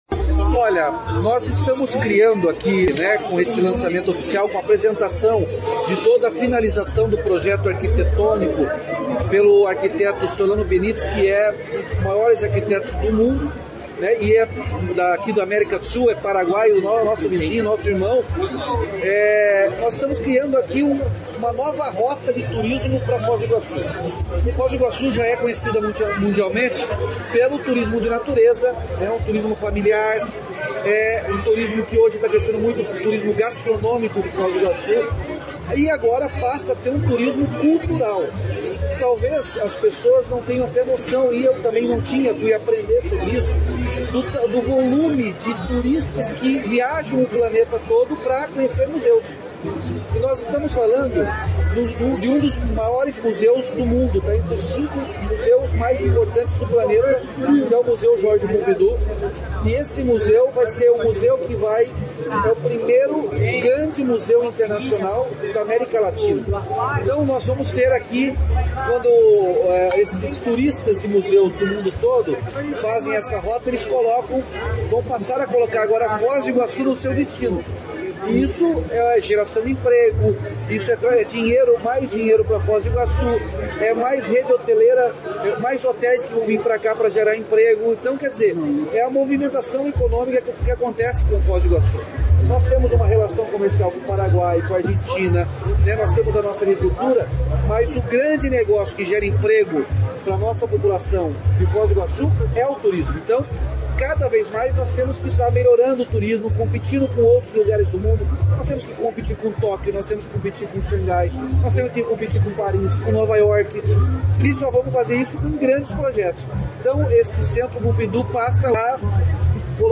Sonora do governador Ratinho Junior sobre o Centre Pompidou